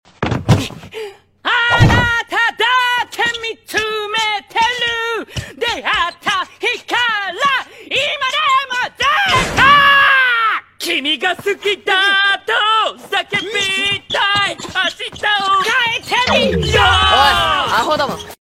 opening and ending OST